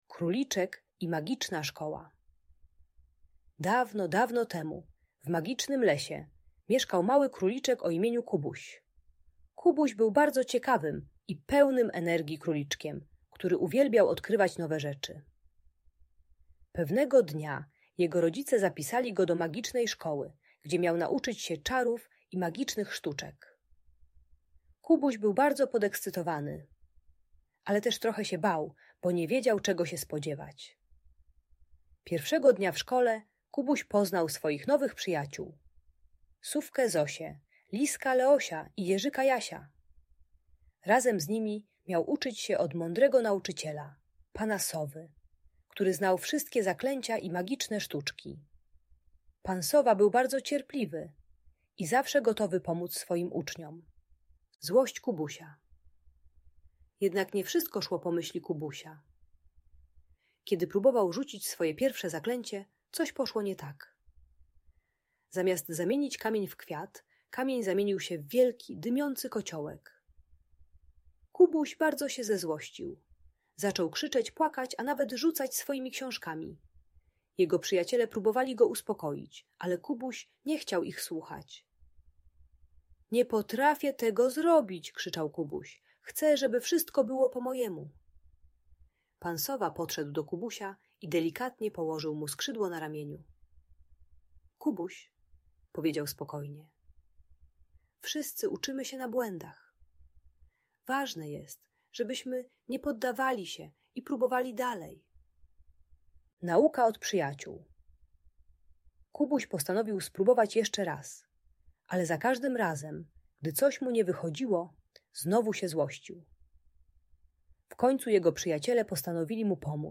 Audiobajka o radzeniu sobie ze złością uczy techniki głębokiego oddechu i próbowania jeszcze raz zamiast krzyków i płaczu. Pomaga dziecku zrozumieć, że błędy są częścią nauki.